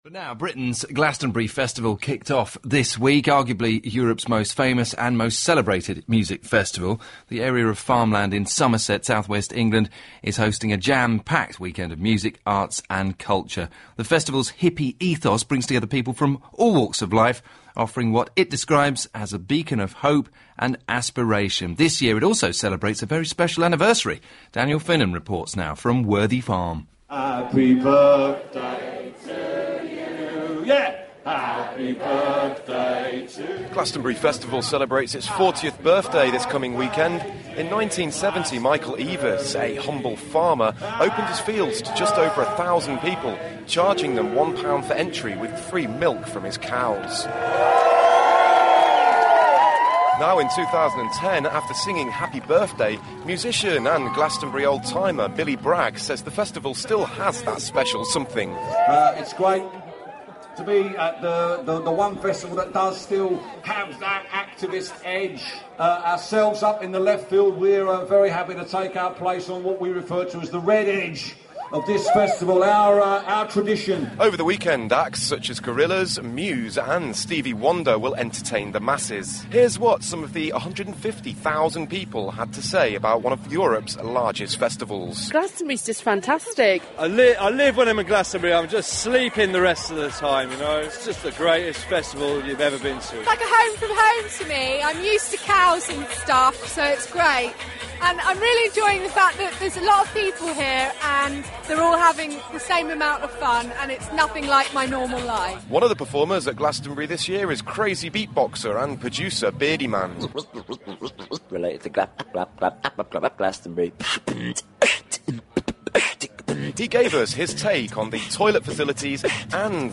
Radio Report